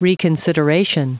Prononciation du mot reconsideration en anglais (fichier audio)
Prononciation du mot : reconsideration